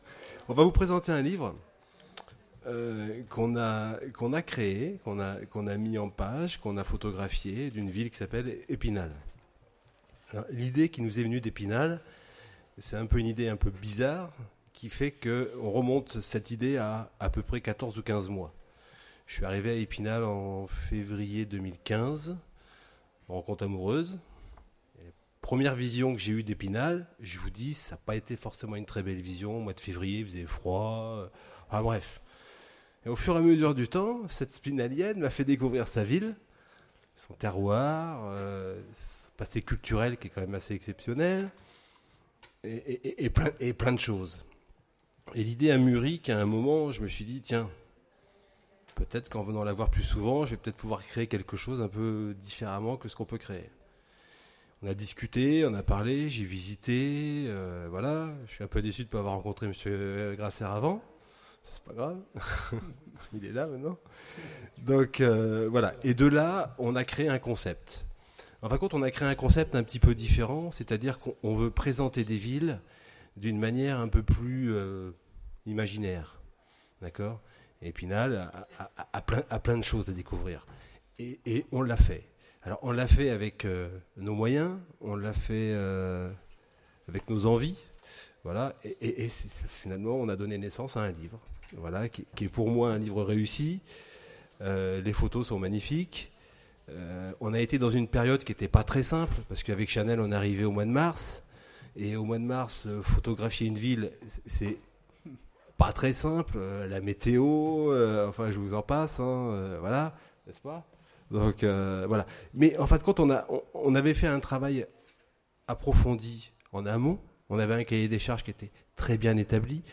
Imaginales 2016 : Conférence Épinal, ville des images…